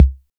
Index of /90_sSampleCDs/Roland - Rhythm Section/DRM_Drum Machine/KIT_TR-808 Kit
DRM 808 K 0M.wav